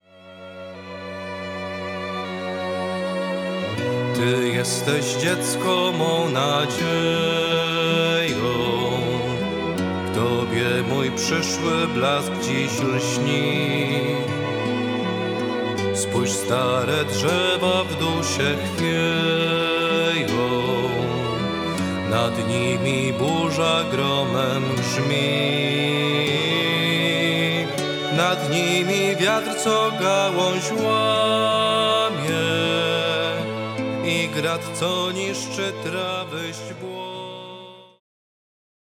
Podkład do piosenki